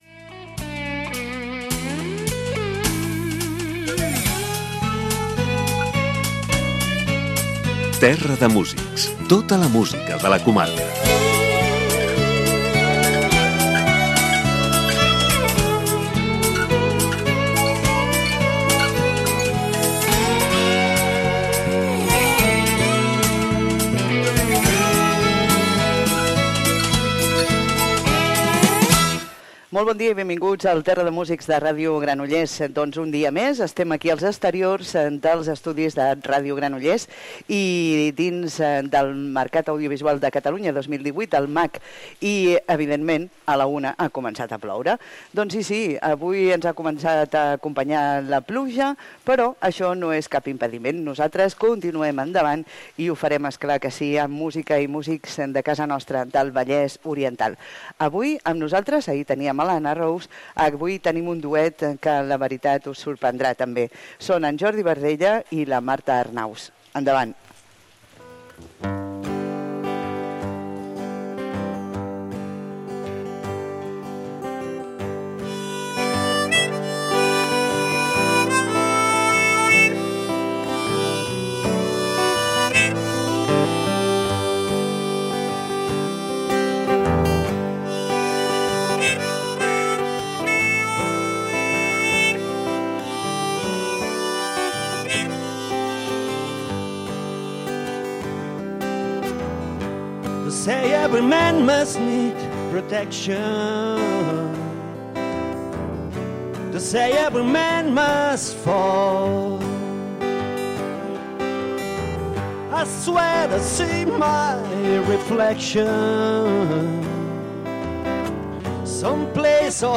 Entrevista i miniconcert
en directe des dels exteriors dels estudis en motiu del MAC 2018.